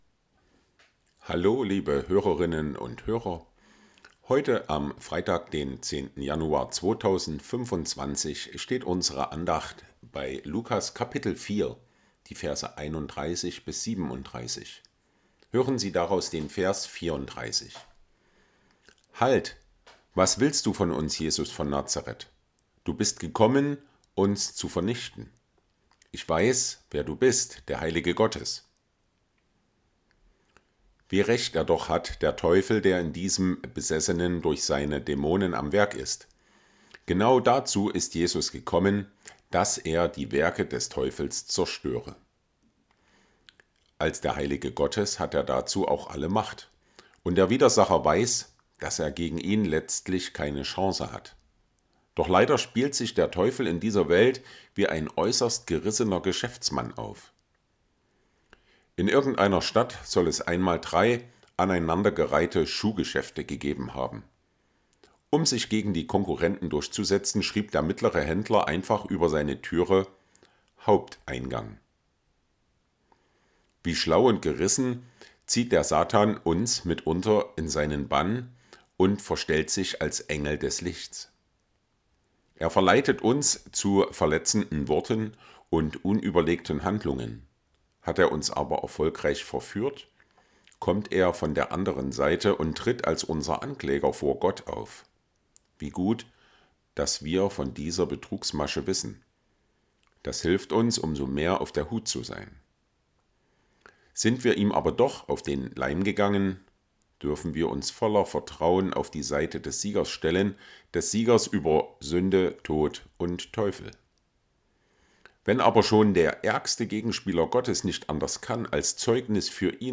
Andacht vom 10.01.2025